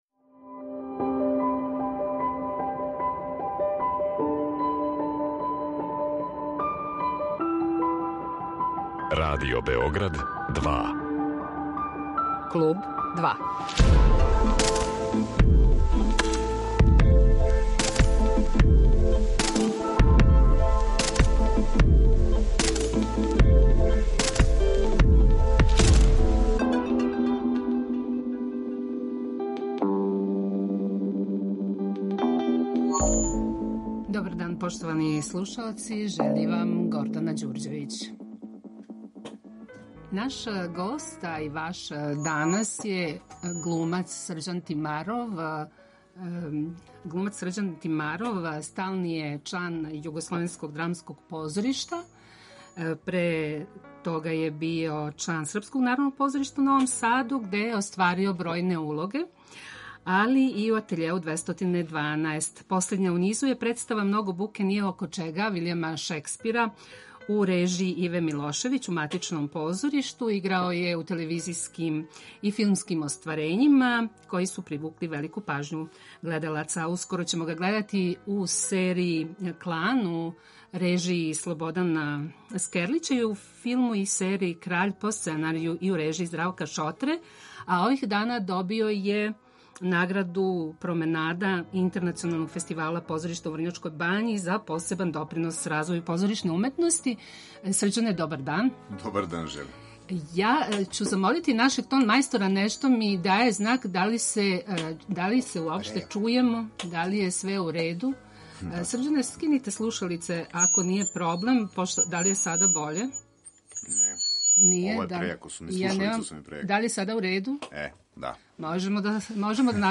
Гост Клуба 2 је глумац Срђан Тимаров.